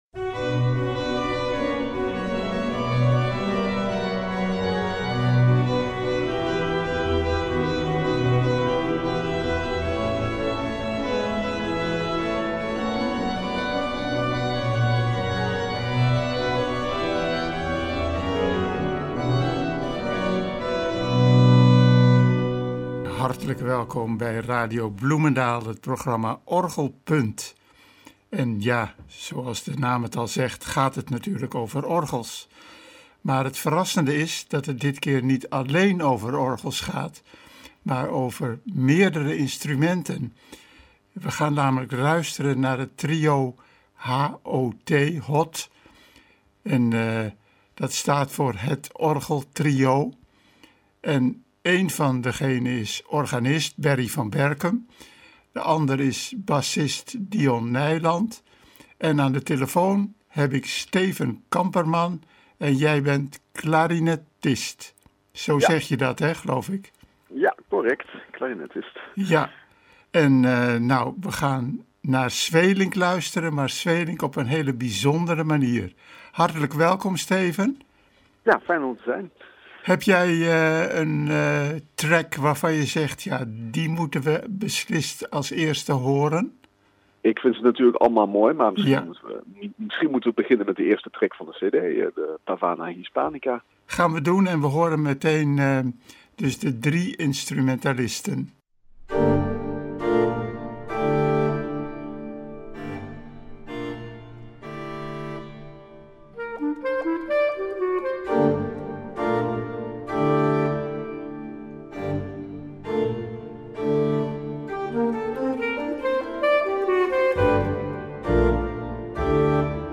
klarinet
contrabas
soms ook compleet verjazzt.